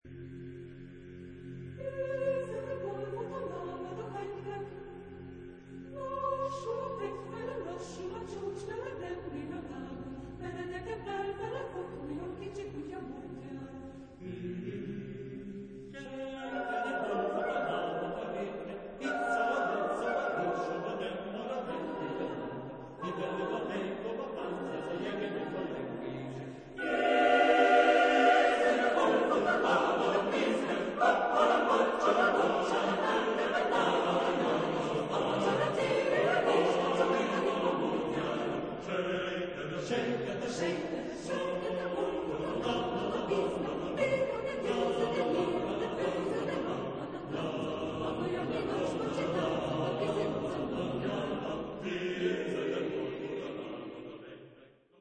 Genre-Style-Forme : Populaire ; Chœur ; Suite
Type de choeur : SATB  (4 voix mixtes )
Tonalité : pentatonique